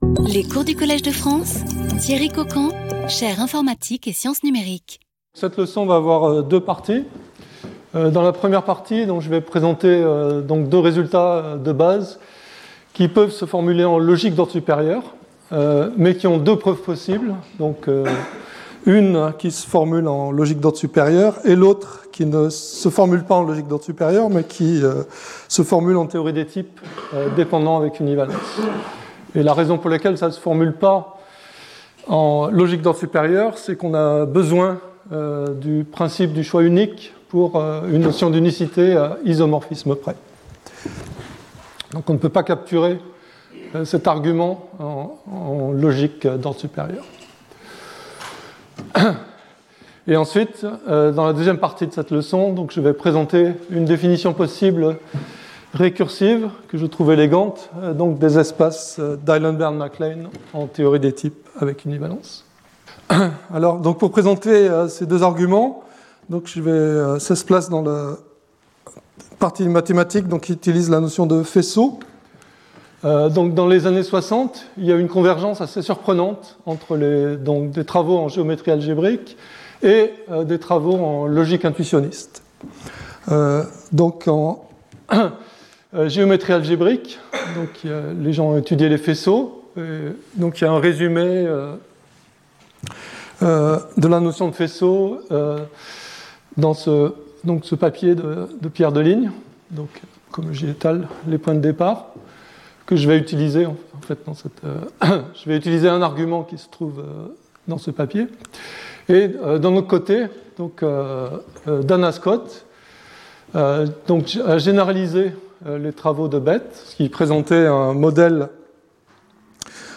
Thierry Coquand Professeur en informatique, Université de Göteborg, Suède
Cours